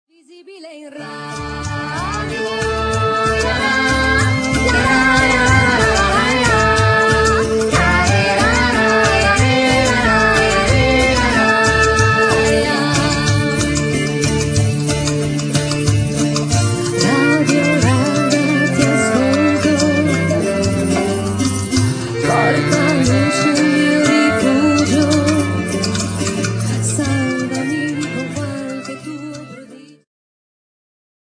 ジャンル Progressive
アコースティック
ローマ出身のアコースティック楽器と女性ヴォーカルによるグループ。